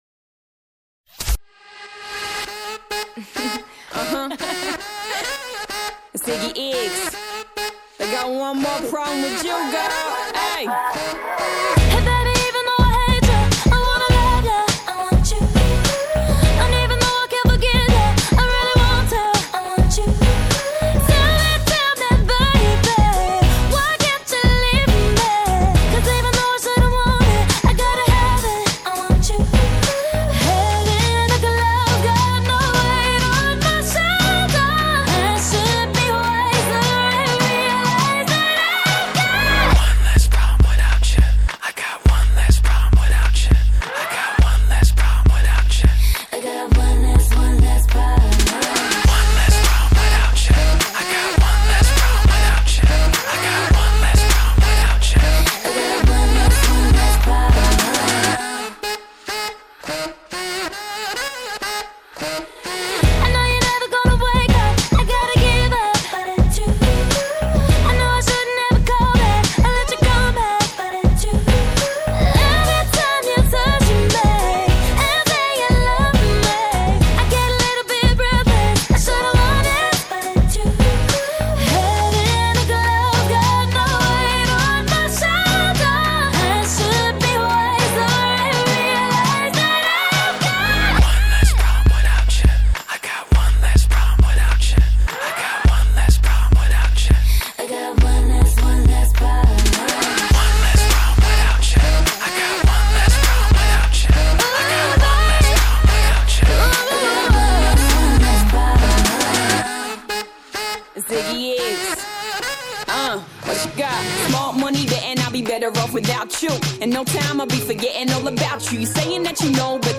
Pop, R&B, Electronic